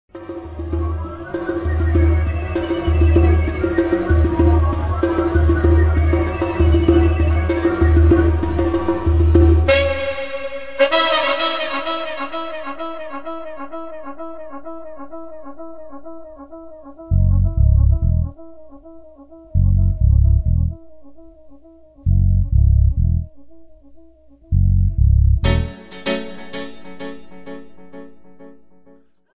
são como pegadas numa estrada de mais de dez anos de reggae.